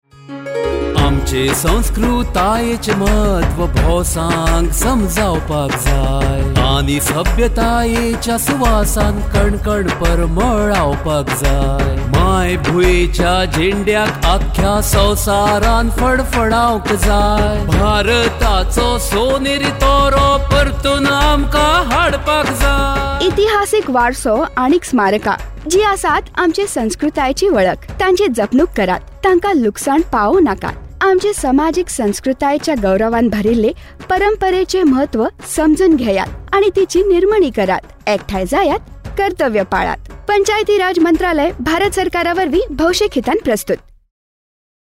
107 Fundamental Duty 6th Fundamental Duty Preserve composite culture Radio Jingle Konkani